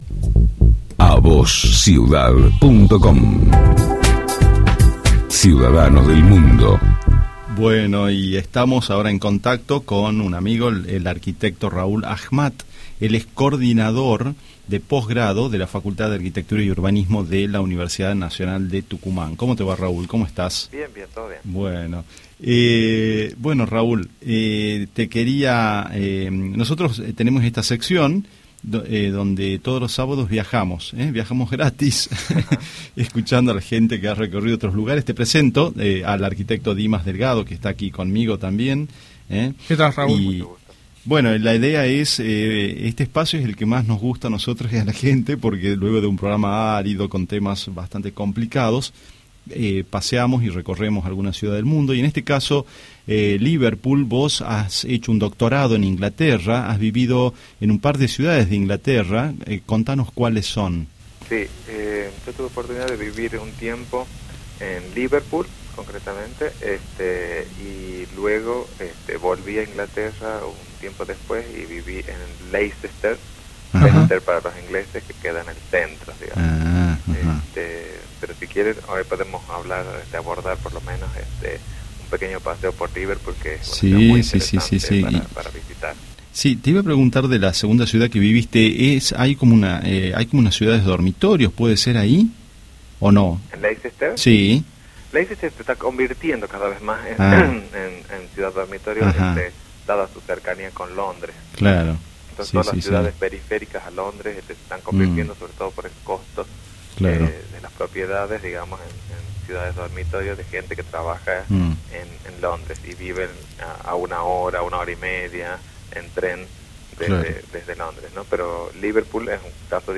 AUDIO DE LA ENTREVISTA